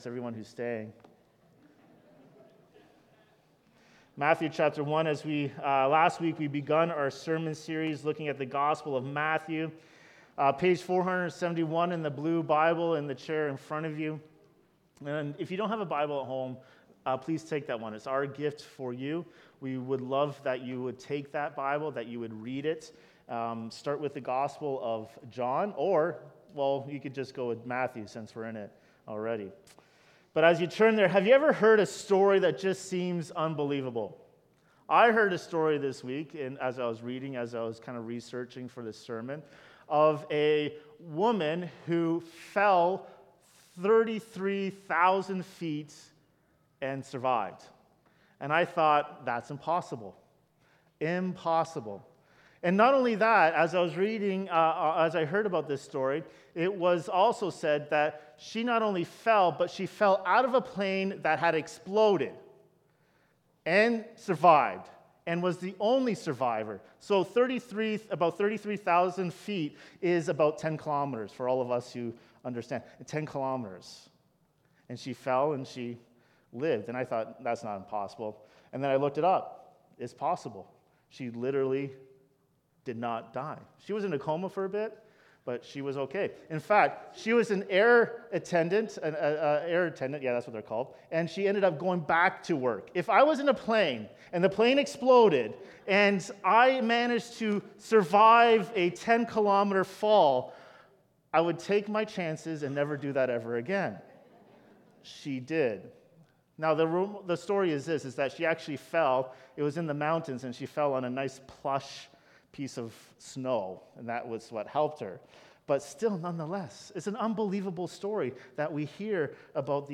The Birth of Jesus Christ | Matthew 1:18–25 Sermon | Knollwood Baptist Church